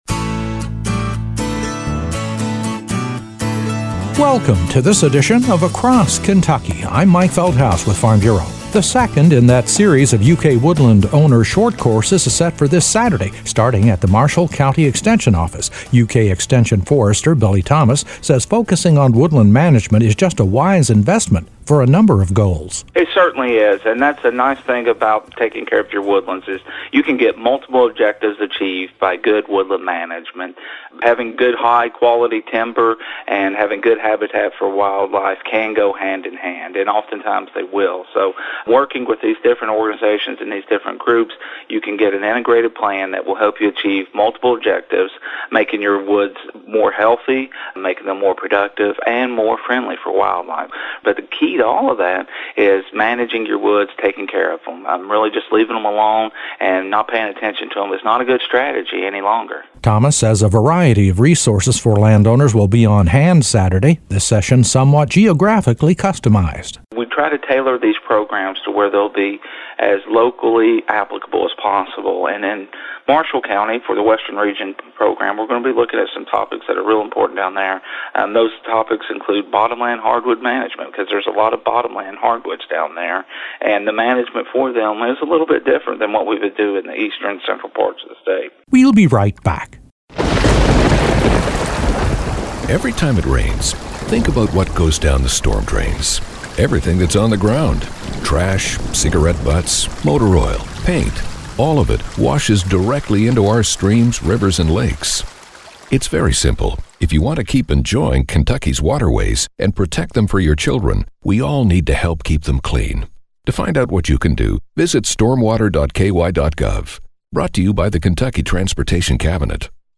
A report on an opportunity this weekend for woodland owners in the Purchase Area of the state. A woodland owner short course is set for this Saturday, starting out at the Marshall County Extension office, providing insight into the value of better management of wooded acreage.